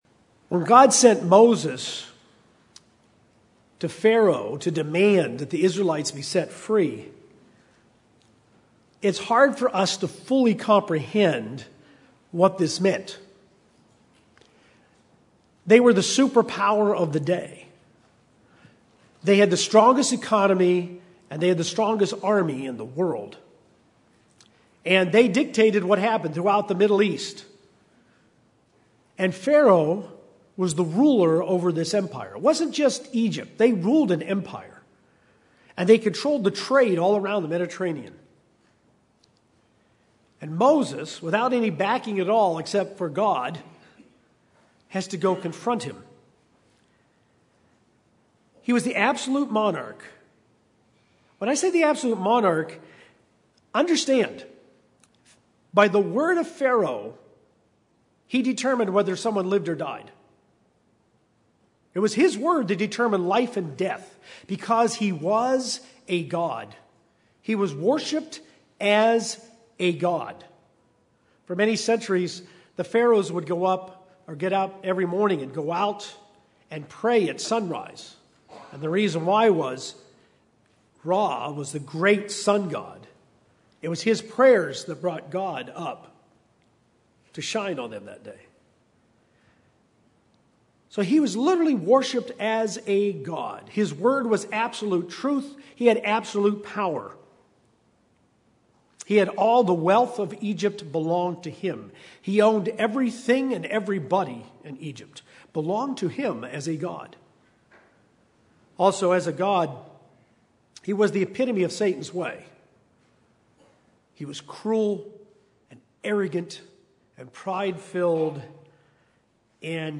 We can learn from Pharaoh as a character in the events of Exodus, his role as a type of Satan and the confrontation between God and evil. This sermon was given on the Last Day of Unleavened Bread.